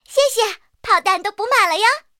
SU-26补给语音.OGG